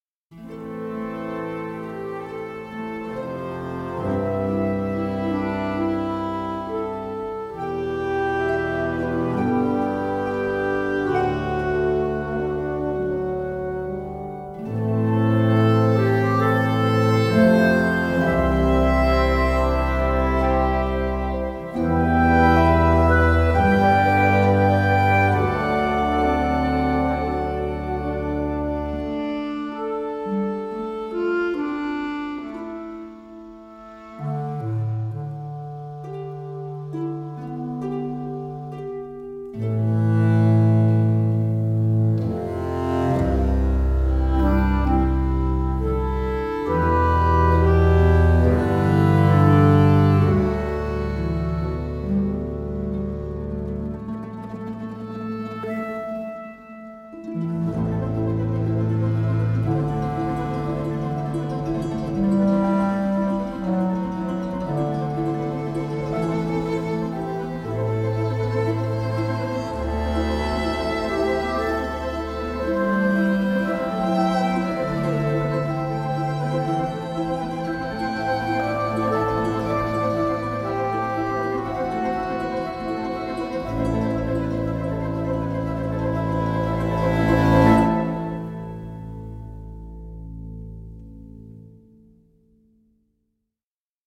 旗舰木管乐器
在Teldex得分舞台上录制，声音自然，圆润，并与其他柏林系列完美融合。
所有乐器都以相同的自然增益水平精心录制，音乐家位于传统管弦乐队的座位位置。